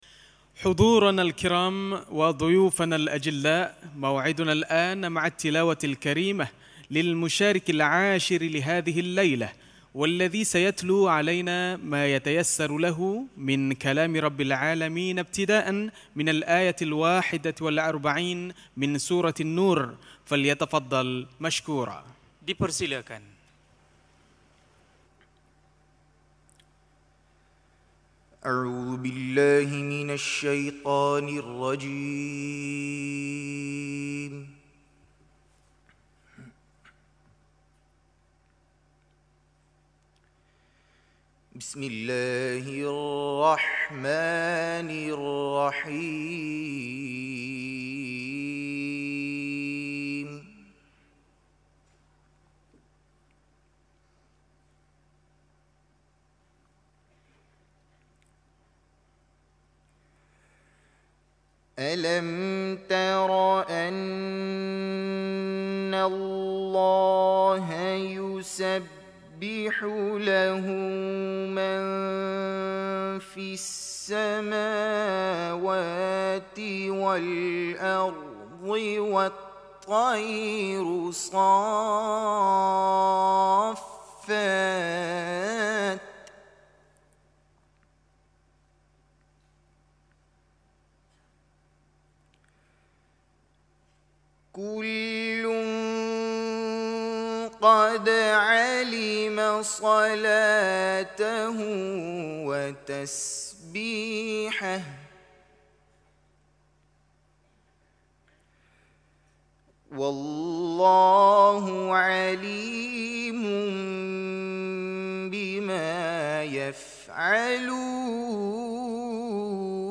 گروه فعالیت‌های قرآنی: شب گذشته در مسابقات بین‌المللی مالزی پنج قاری از میان آقایان شرکت‌کننده تلاوت داشتند که تحلیلی از تلاوت آنها خواهیم داشت.